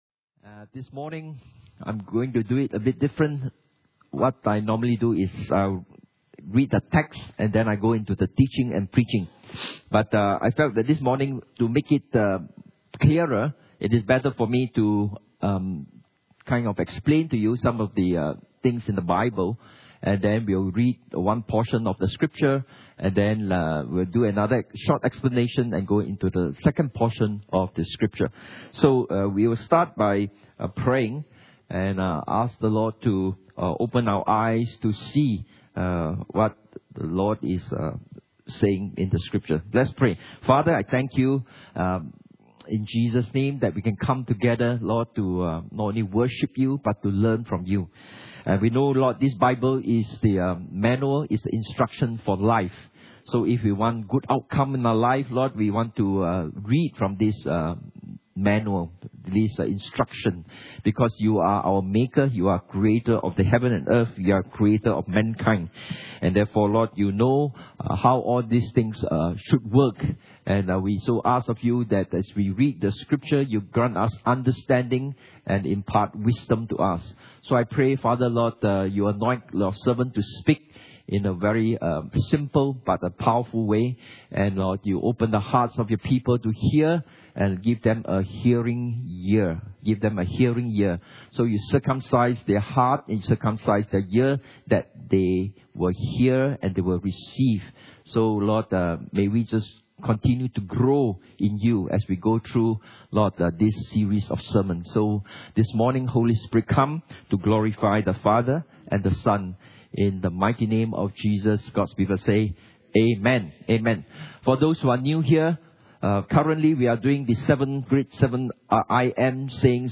The 7 I AM's of Jesus Service Type: Sunday Morning « The 7 I AM’s of Jesus P3